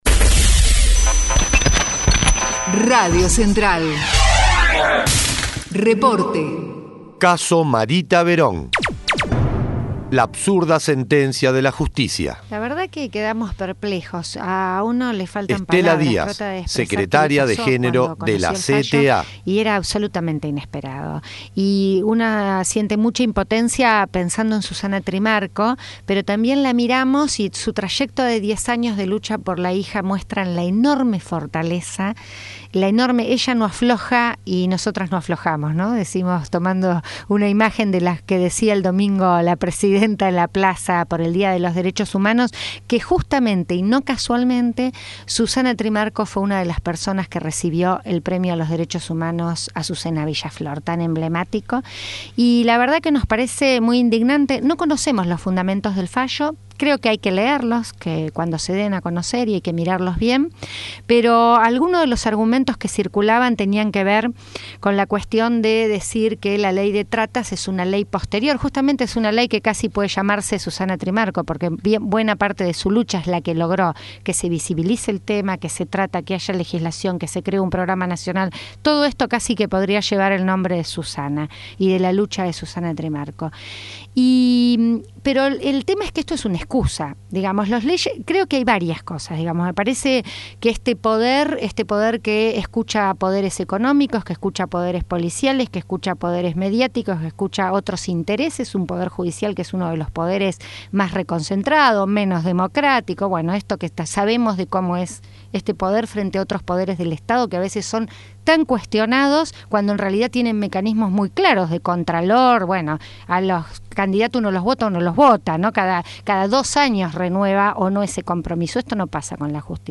Declaraciones de Estela Díaz